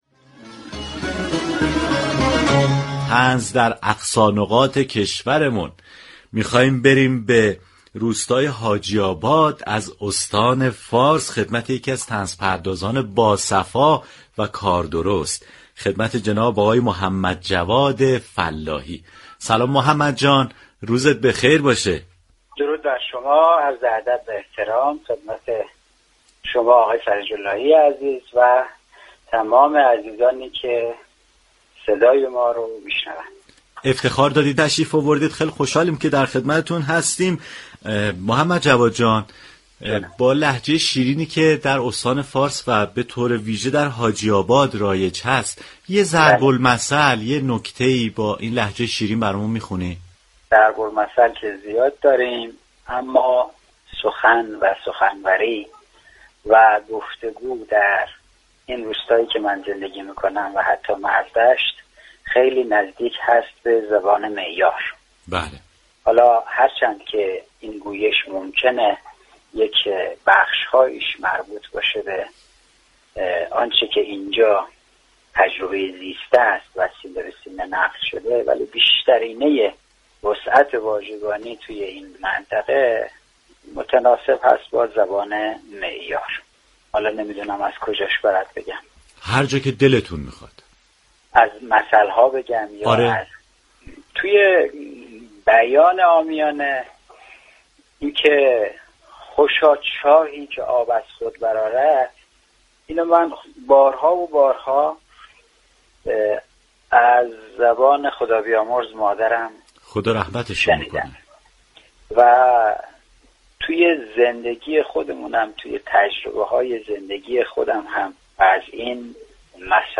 شنونده گفتگوی رادیو صبا